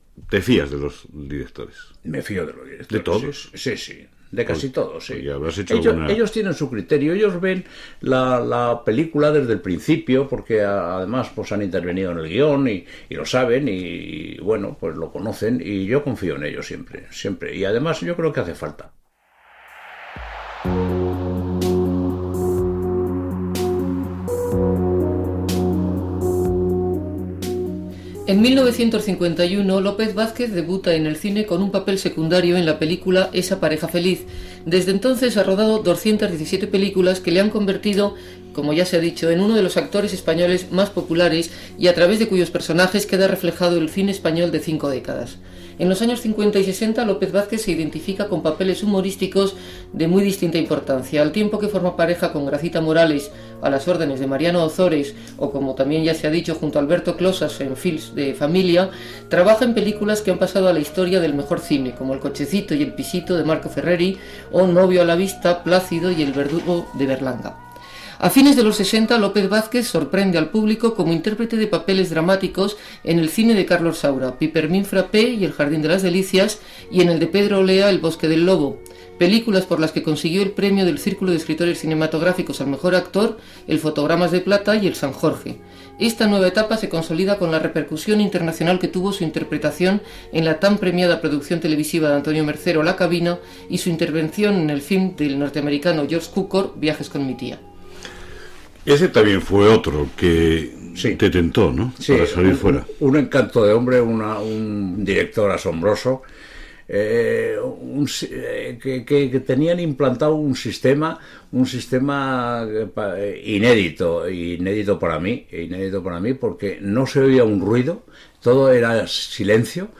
a7cdac3b0d2e223f9488b561a40e9872bc705f29.mp3 Títol Radio Nacional de España Emissora Radio Nacional de España Barcelona Cadena RNE Titularitat Pública estatal Nom programa La máscara Descripció Pefil biogràfic professional i entrevista a l'actor José Luis López Vázquez.